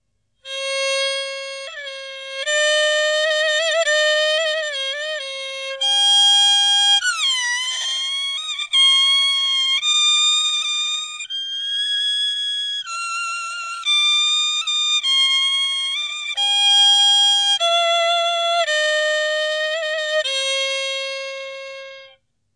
Đàn cò líu
[IMG]àn Cò Líu trong trẻo, chói sáng, biểu đạt những tình cảm đẹp đẽ, cao thượng, vui tươi, sôi nổi... có thể gay gắt, sắc nhọn nhất là ở những âm cao.